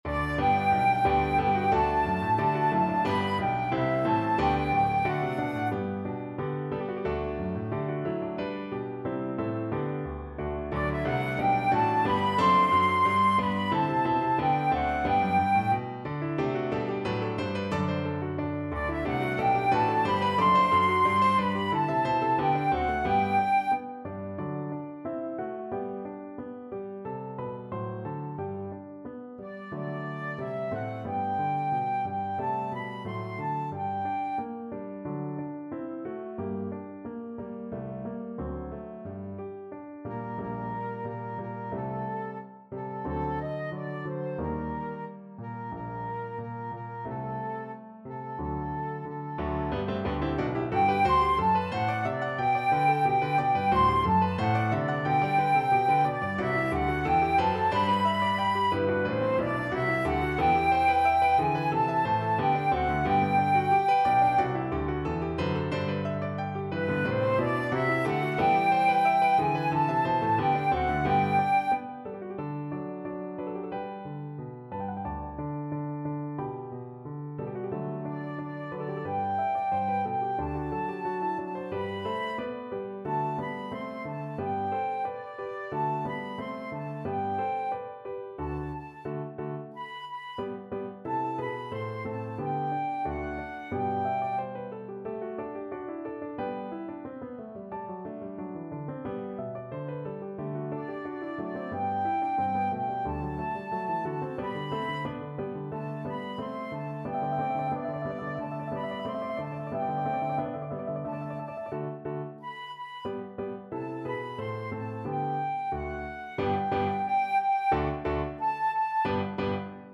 Classical Haydn, Franz Josef The Heavens are Telling from The Creation (Die Schöpfung) Flute version
Flute
G major (Sounding Pitch) (View more G major Music for Flute )
Allegro =180 (View more music marked Allegro)
4/4 (View more 4/4 Music)
G5-C7
Classical (View more Classical Flute Music)
haydn_heavens_telling_FL.mp3